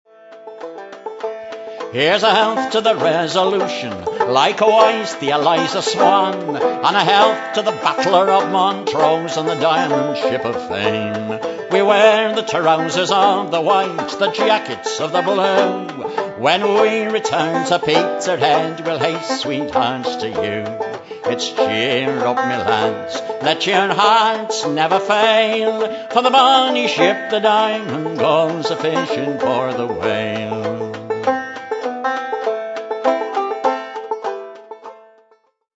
vocals, concertina & banjo
violin & viola
harp
whistle